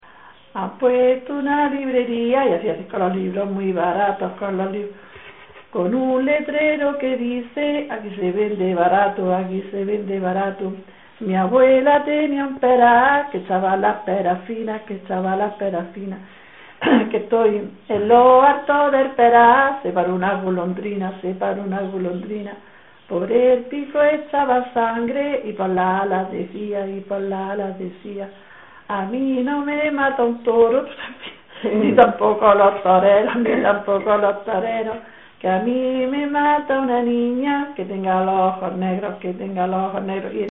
Materia / geográfico / evento: Canciones de corro Icono con lupa
Ácula (Ventas de Huelma, Granada. Pedanía) Icono con lupa
Secciones - Biblioteca de Voces - Cultura oral